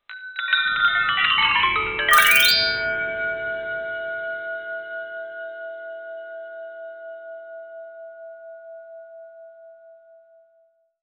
UI_SFX_Pack_61_61.wav